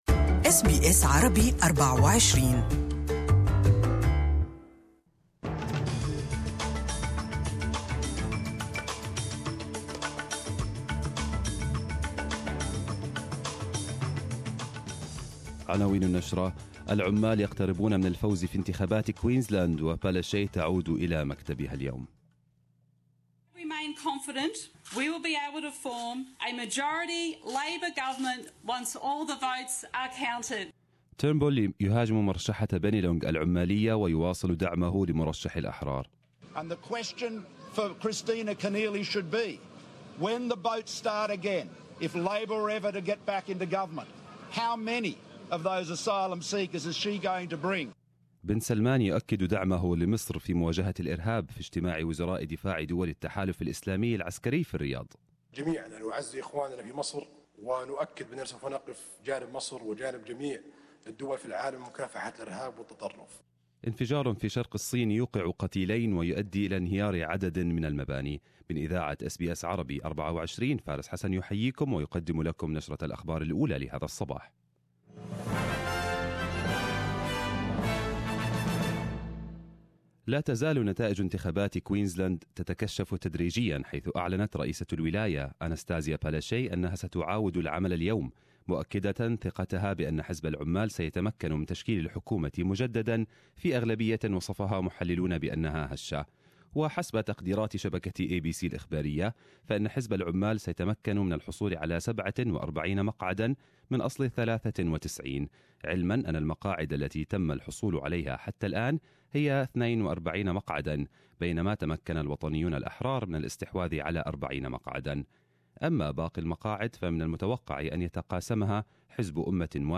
استمعوا إلى نشرة مفصّلة للأنباء من SBSعربي 24.